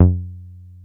303 F#2 3.wav